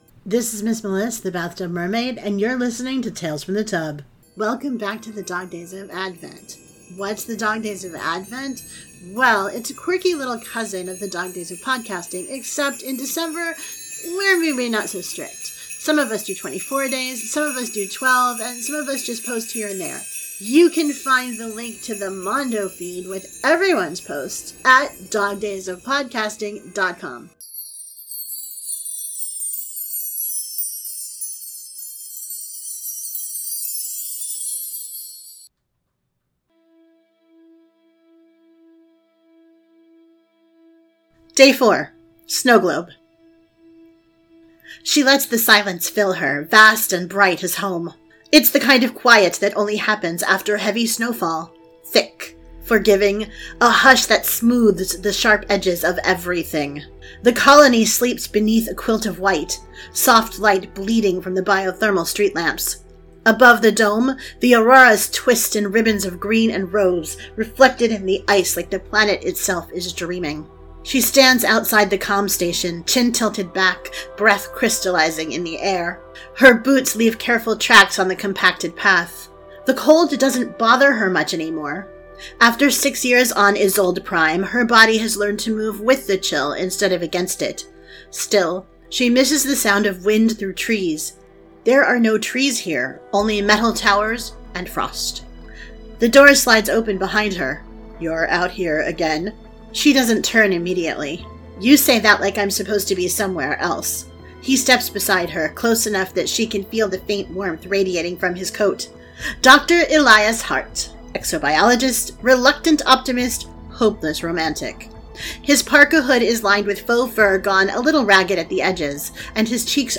• Sound Effects and Music are from Freesound and UppBeat